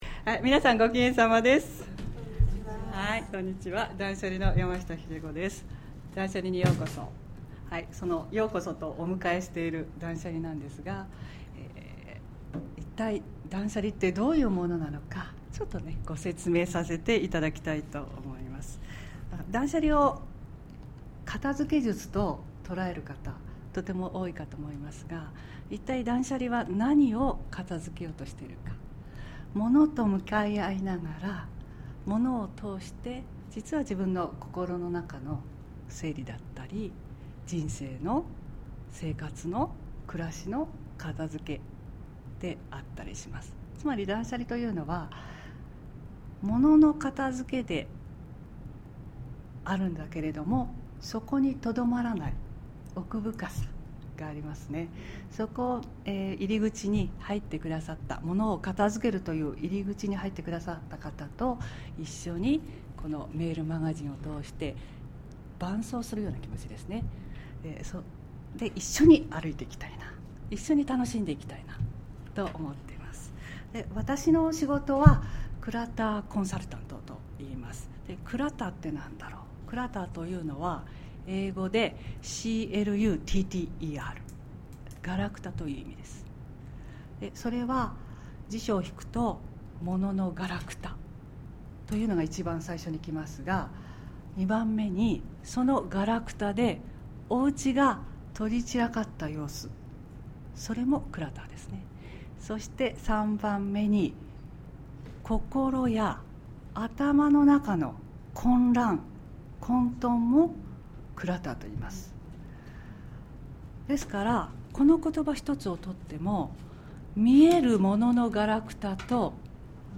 やましたひでこ音声セミナー無料プレゼント « 断捨離® やましたひでこ公式サイト
メルマガを読む前に知っていただきたい断捨離の基本や、やましたひでこがメルマガを通してお伝えしたいことを語っていますので、どうぞしっかりと活用してみてくださいね。